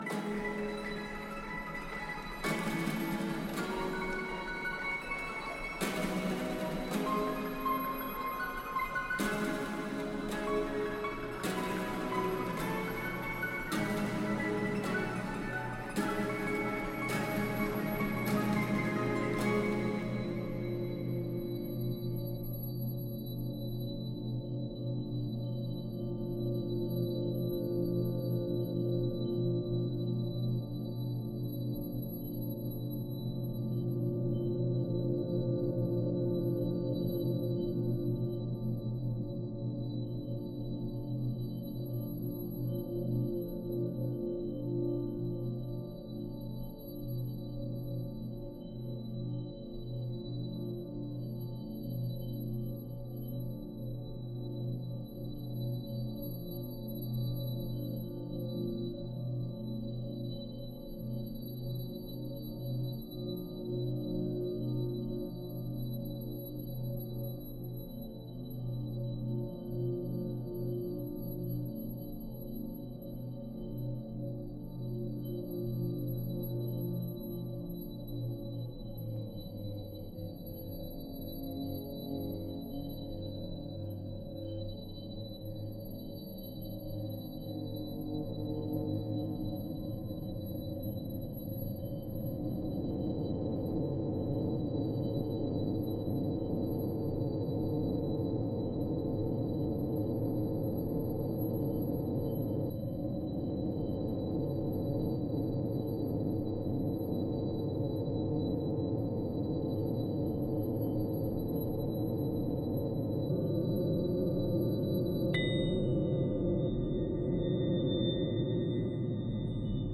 buskers